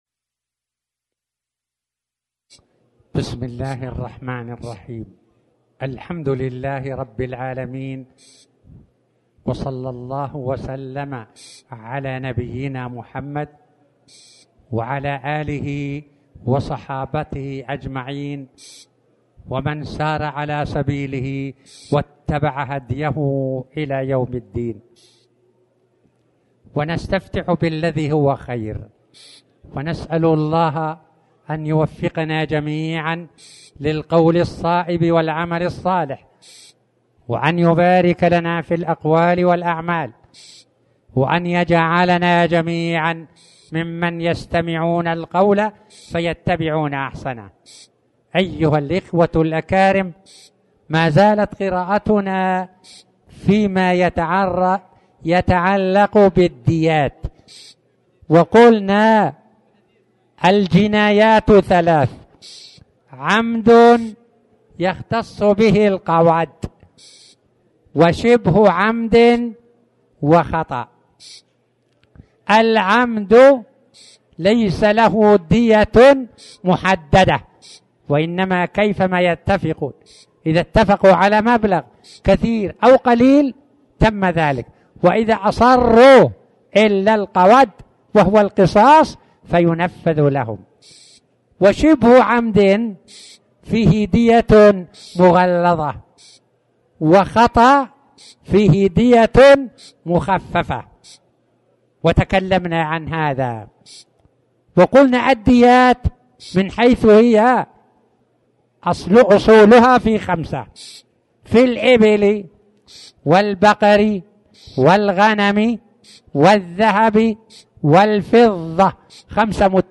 تاريخ النشر ٢ ذو القعدة ١٤٣٩ هـ المكان: المسجد الحرام الشيخ